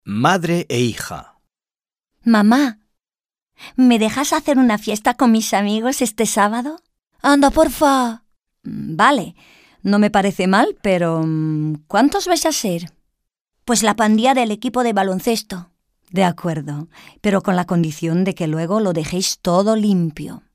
Dialogue - Madre e hija